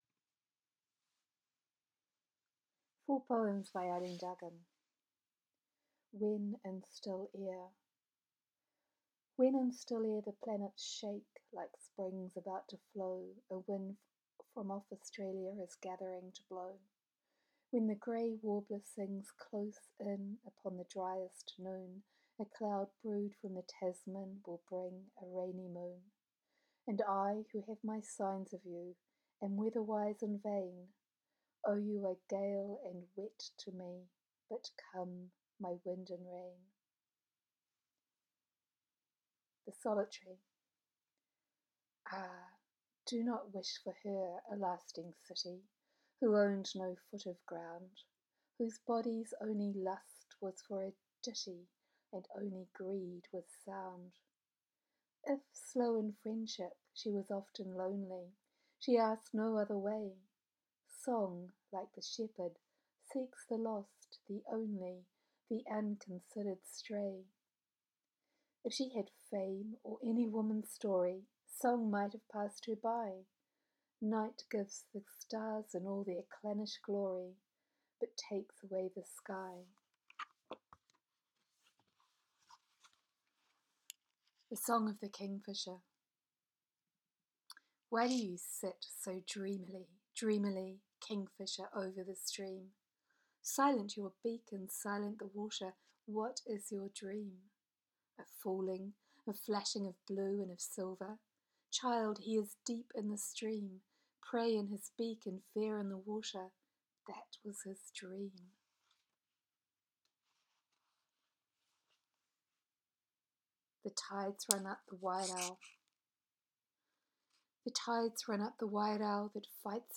I read Eileen Duggan’s: